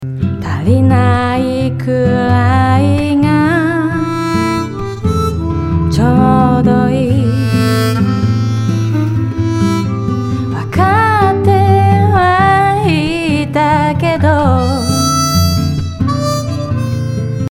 ハーモニカのトラックにかかっているリバーブの明暗を比べてみましょう。
クリーンで、響きの成分が少し多く硬い印象があるかな？と思いましたがどうでしょう？